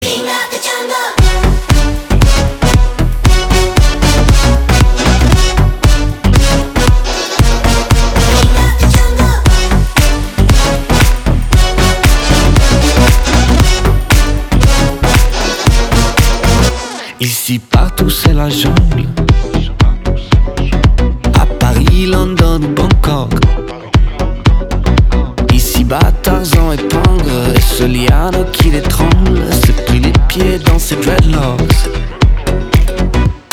• Качество: 320, Stereo
заводные
dance
future house
Bass
трубы